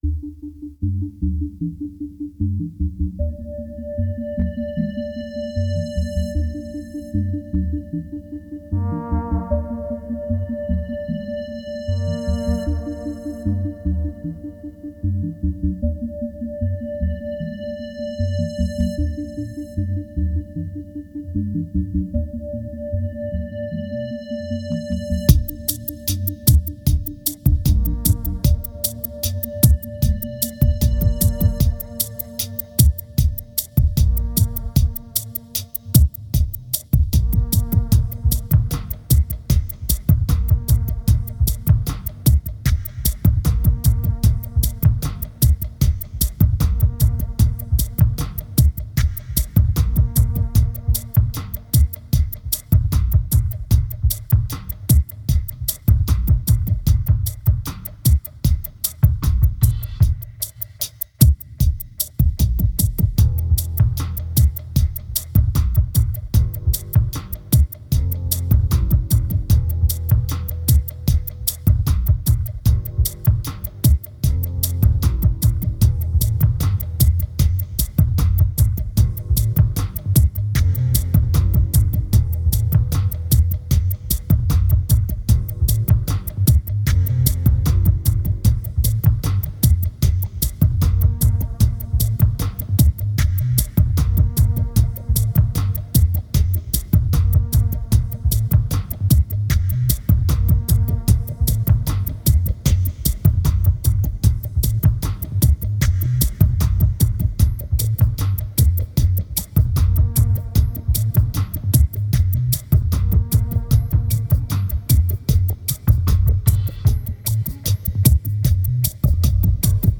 2411📈 - 46%🤔 - 76BPM🔊 - 2010-01-06📅 - 11🌟